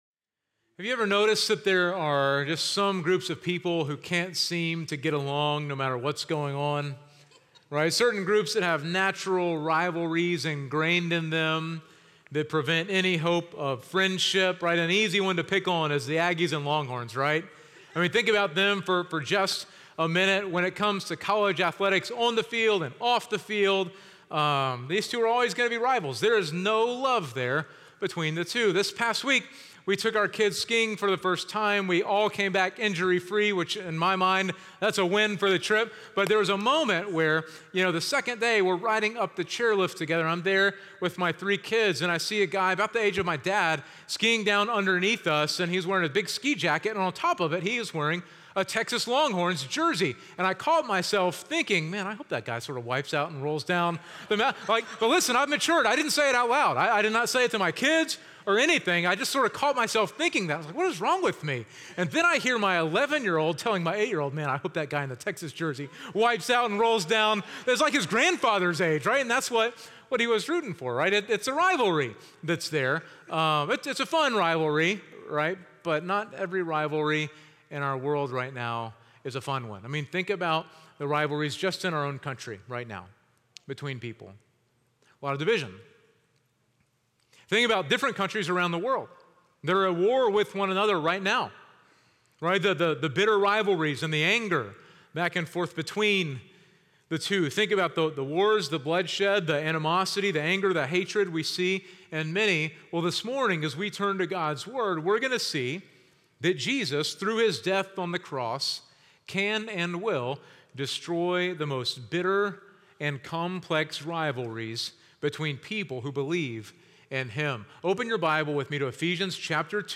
Sunday Sermons – Media Player